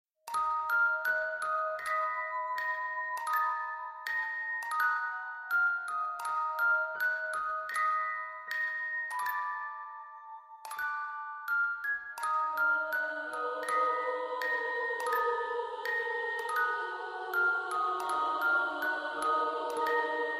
Жуткая музыка